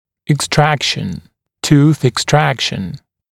[ɪk’strækʃn] [tuːθ ɪk’strækʃn] [ek-] [ик’стрэкшн] [ту:с ик’стрэкшн] [эк-] удаление (удаление зуба)